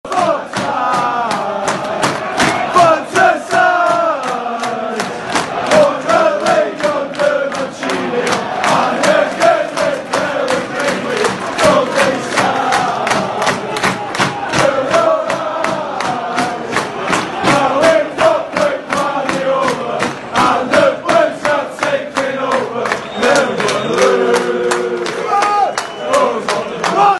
MCFC Fan Chants